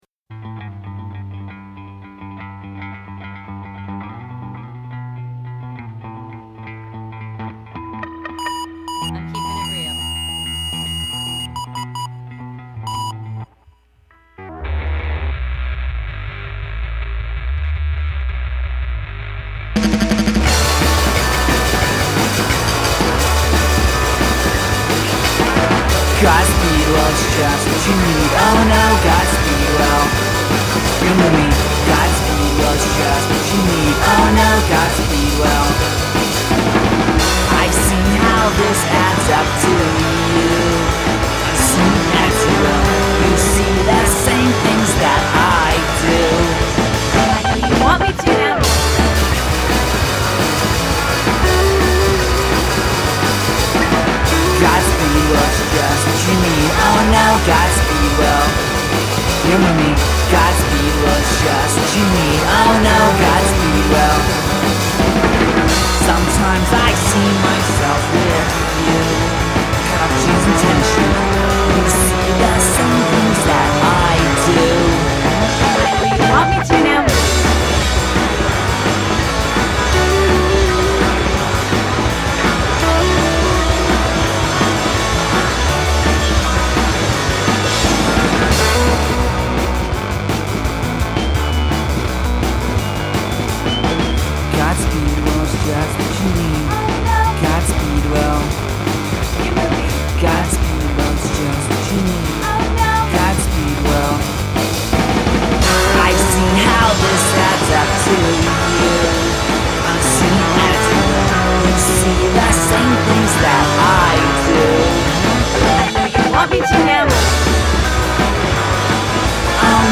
twee pop